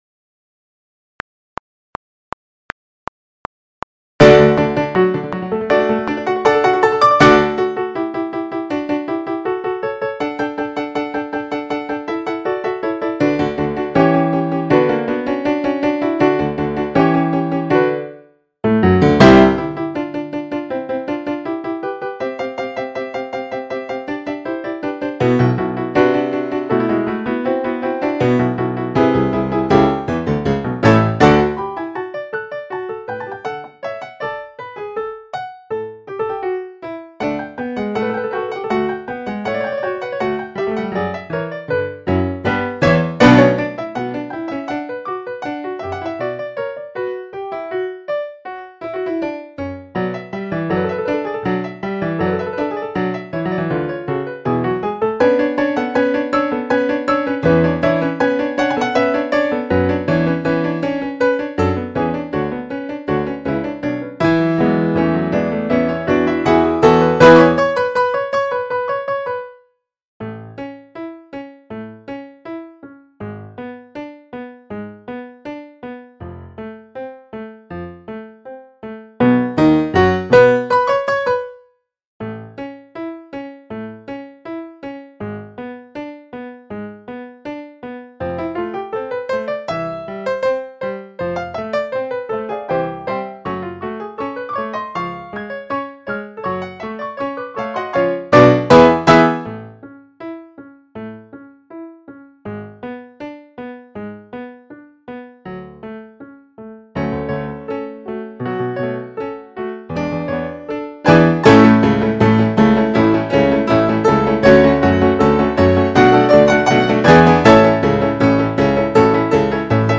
MIDI Practice Tracks:
Quarter note = 160